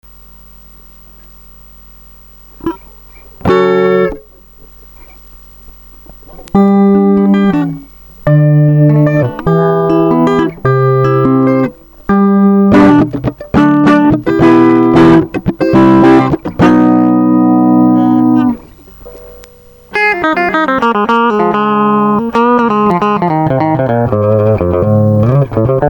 Чистый звук моей электрухи :hack: